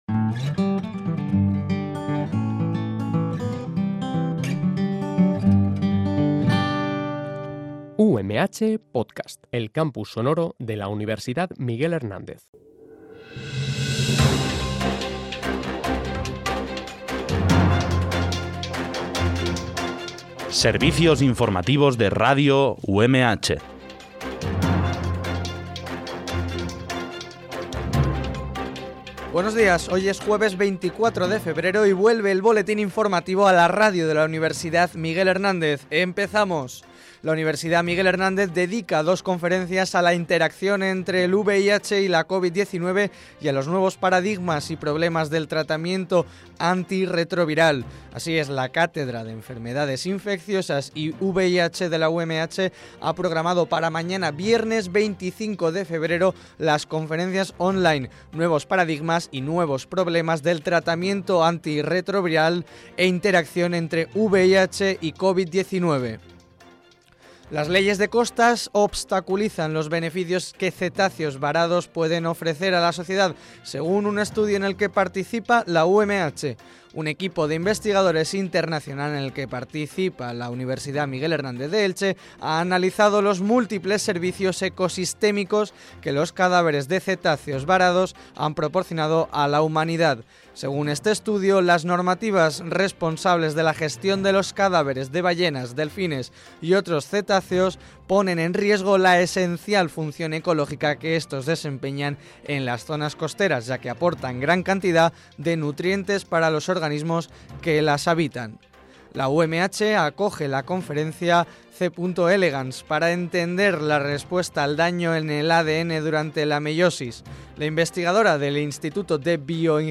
BOLETÍN INFORMATIVO UMH